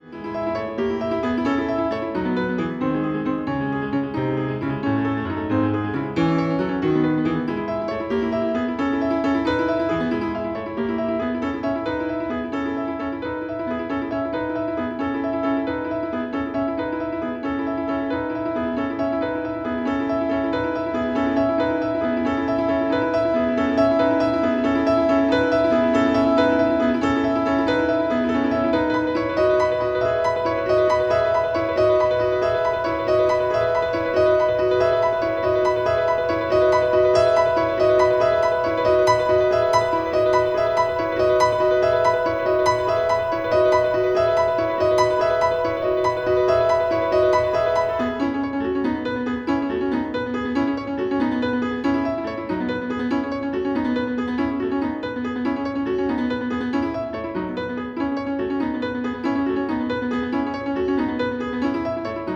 on the Post Piano